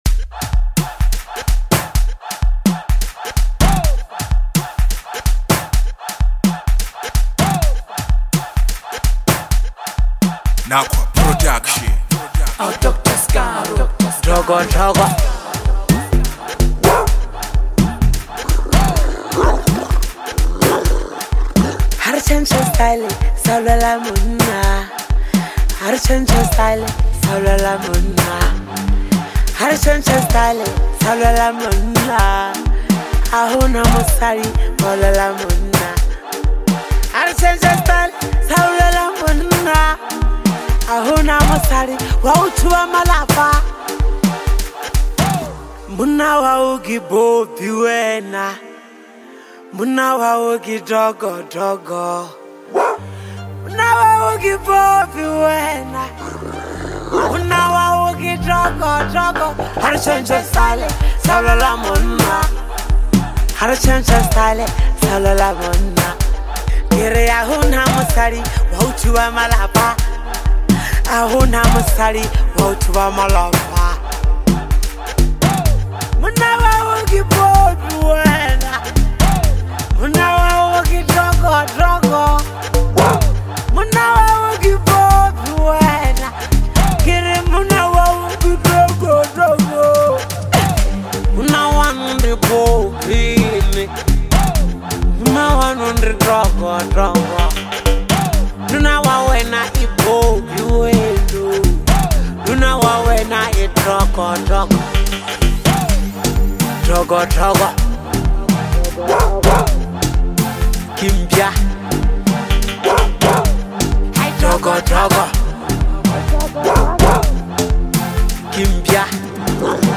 is a vibrant and high energy track